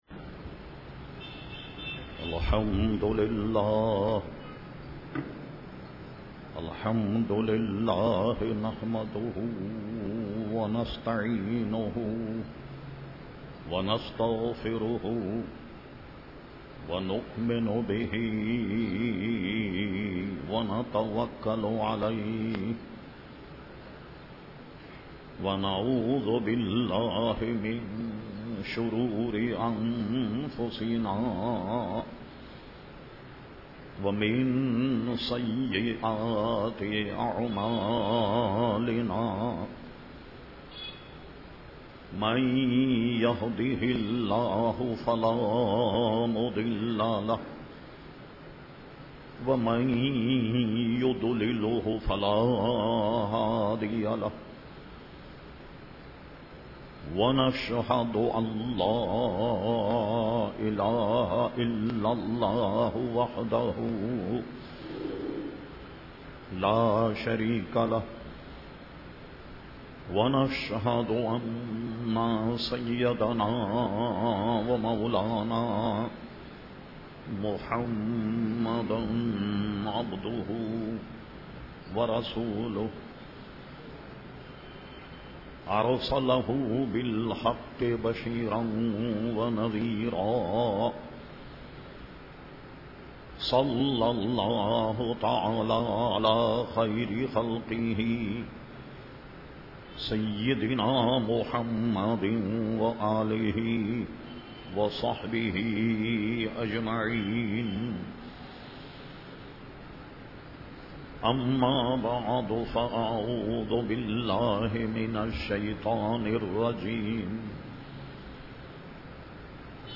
تقاریر